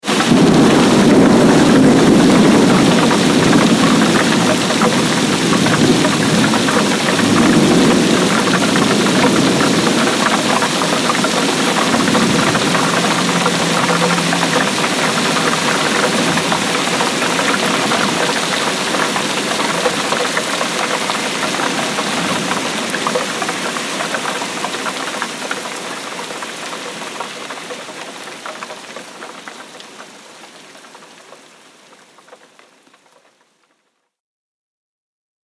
دانلود آهنگ طوفان 1 از افکت صوتی طبیعت و محیط
دانلود صدای طوفان 1 از ساعد نیوز با لینک مستقیم و کیفیت بالا
جلوه های صوتی